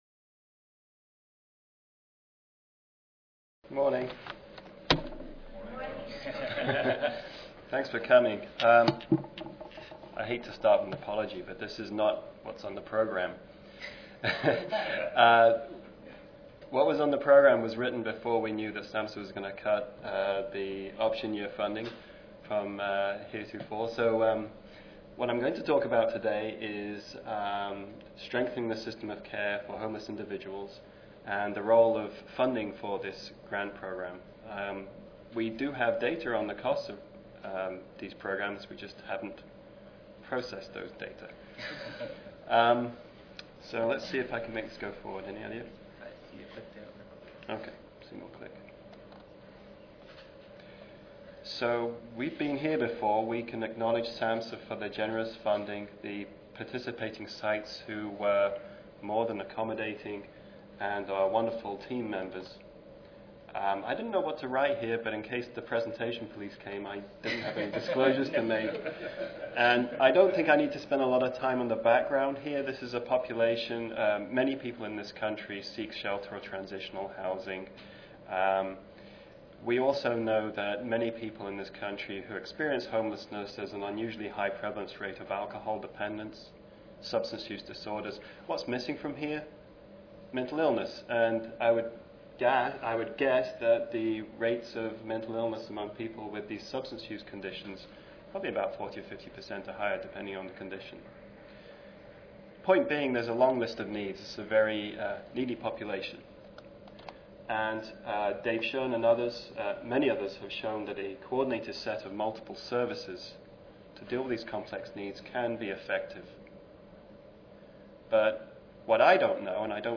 This presentation is one of a panel on a cross-evaluation of 25 recent grantees of the Grants to Benefit Homeless Individuals program, funded by the Substance Abuse and Mental Health Services Administration (SAMHSA). The presentation reports on the approach and preliminary findings of the economic component of the evaluation.